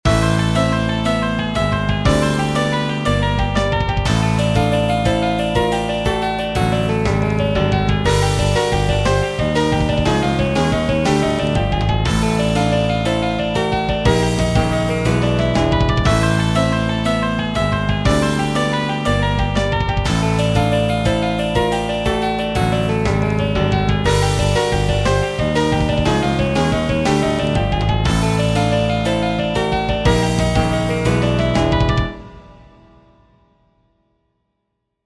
Short 120bpm loop in 17edo, retuned to 19edo
17edo_demo_but_in_19edo_2.mp3